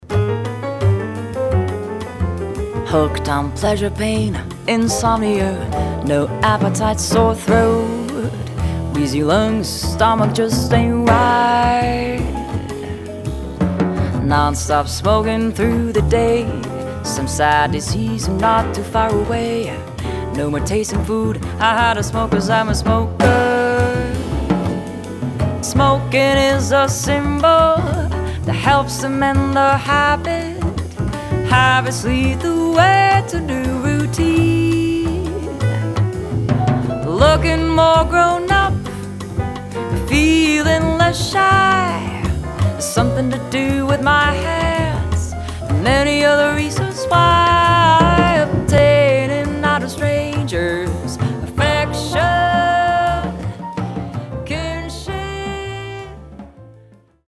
Vocalist
Grand Piano
Double-Bass
Drums
Guitar
Violin
Trombone